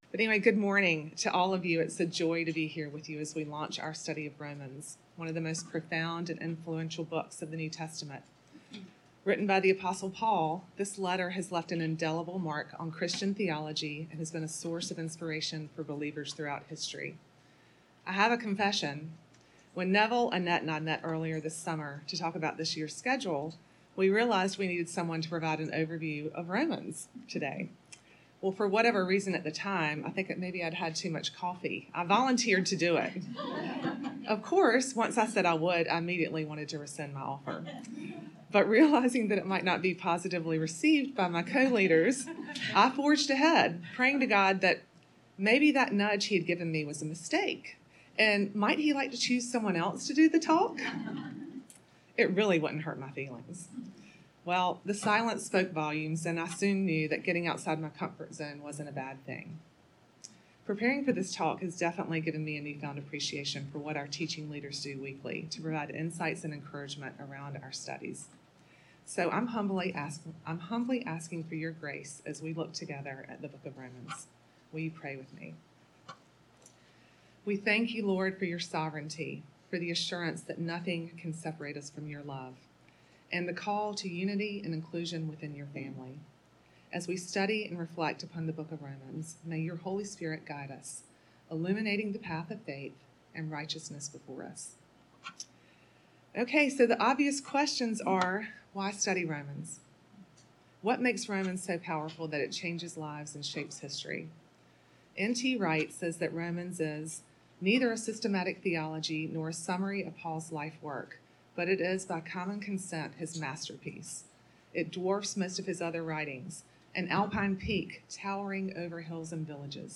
Women of the Word Wednesday Teaching Introduction: Romans 1-16 Sep 20 2023 | 00:20:58 1x 00:00 / 00:20:58 Subscribe Share RSS Feed Share Link Embed